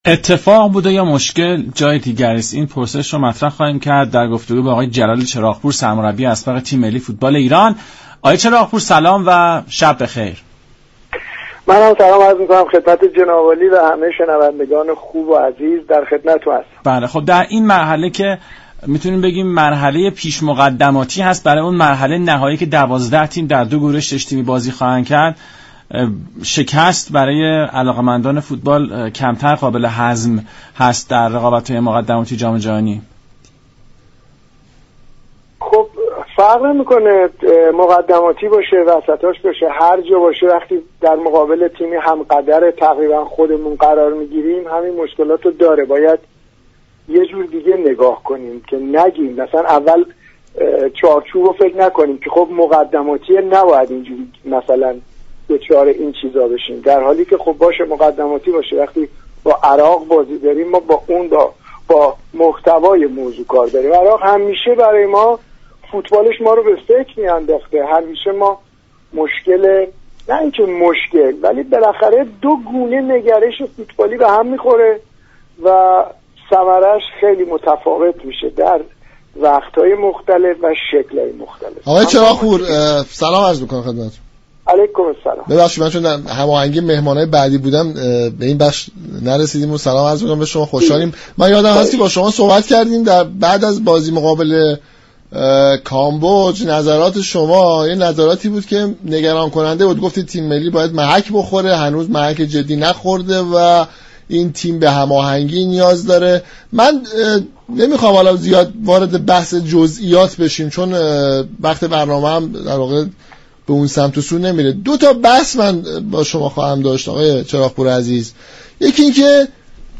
«جلال چراغپور» سرمربی اسبق تیم ملی ایران در گفت و گو با رادیو ایران گفت: نداشتن اردوهای تداركاتی، عامل باخت ایران در برابر عراق نبوده و به نظر می رسد مشكلات فنی تیم علت اصلی این ماجرا بوده است.